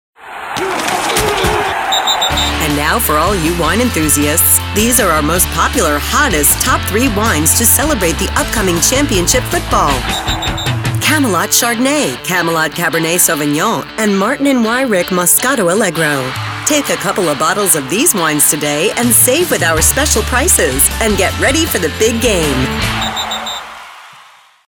Retail Radio Production That Creates Results
Alliance Wines Ad